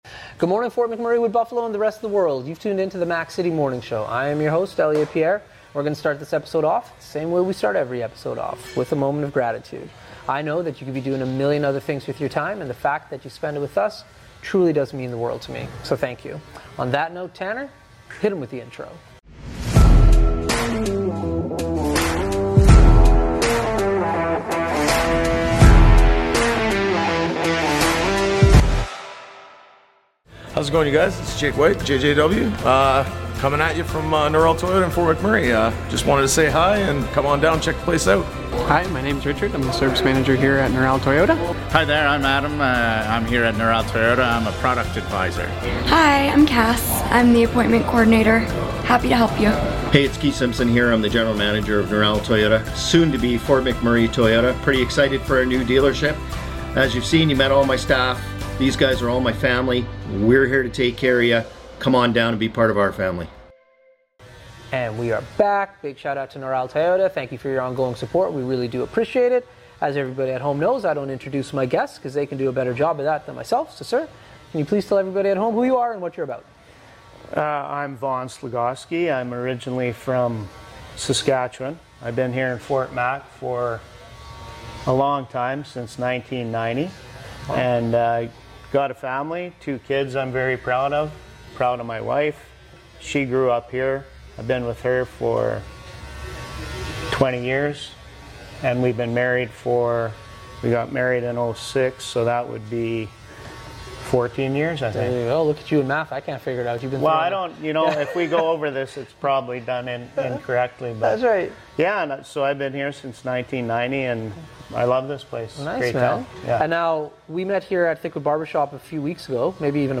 on location at Thickwood Barbershop!